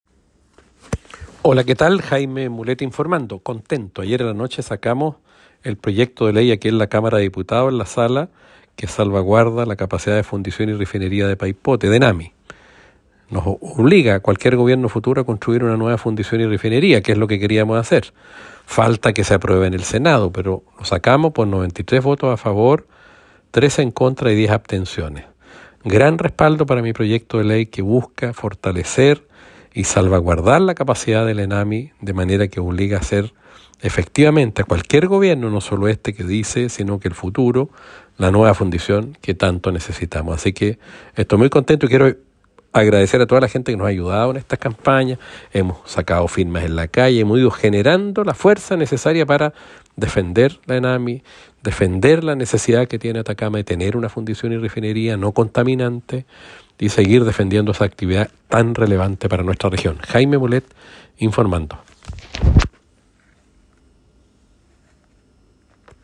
Audio del Diputado Jaime Mulet sobre la aprobación del proyecto de ley que busca resguardar la capacidad de fundición y refinería de Enami